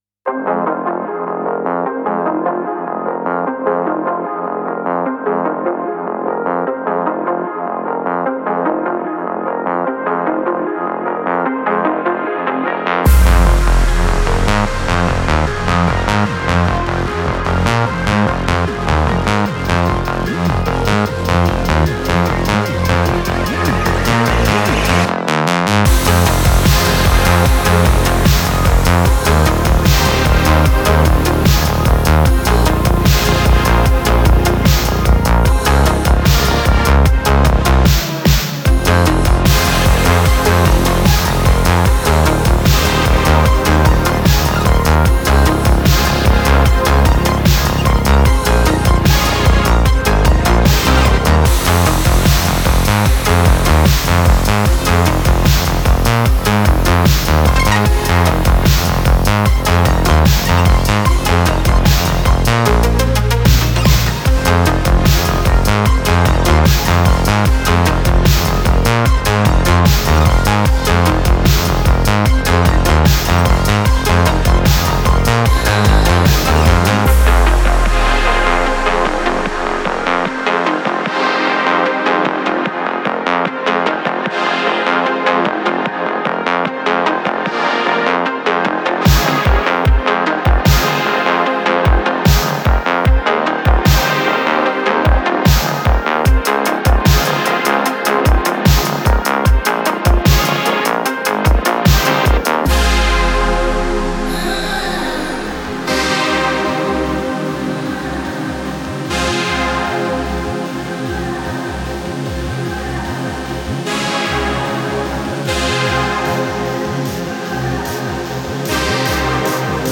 Synthwave Electro Pop